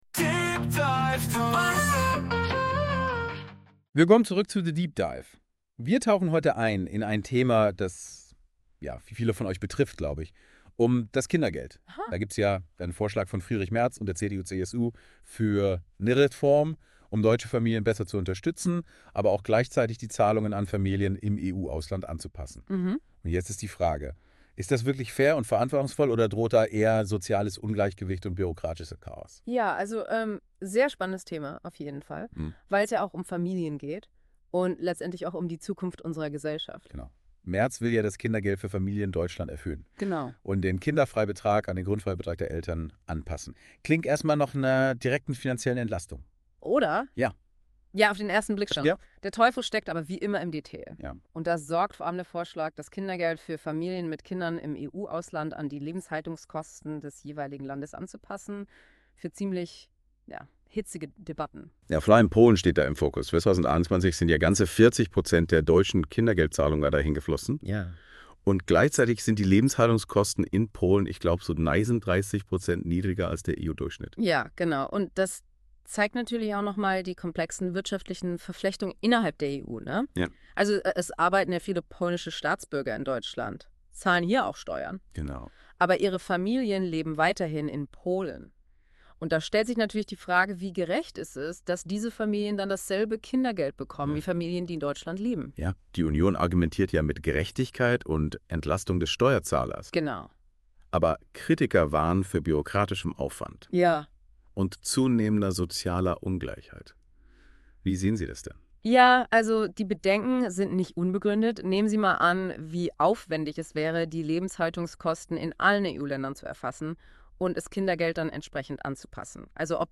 Unsere beiden amerikanischen "Deep Dive Deutsch" Hosts unterhalten sich heute über die geplante Kindergeld-Kürzung.